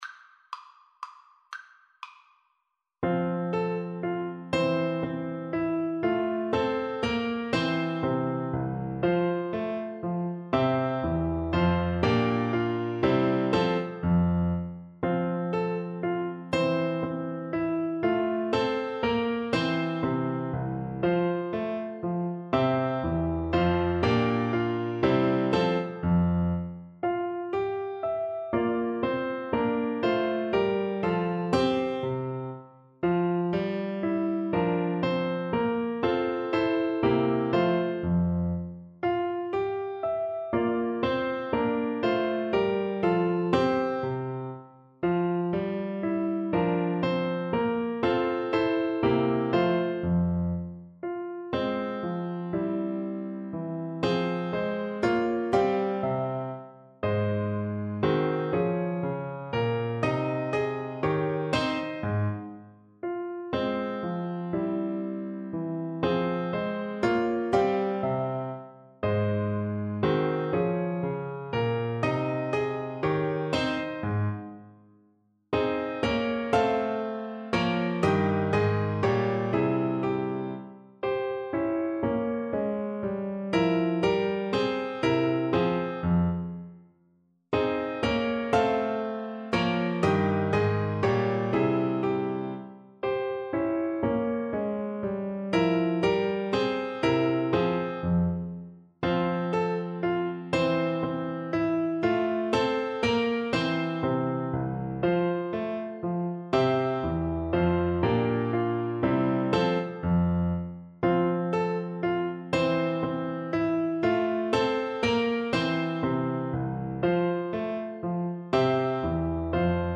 Tempo di minuetto, con brio
3/4 (View more 3/4 Music)
Classical (View more Classical Bassoon Music)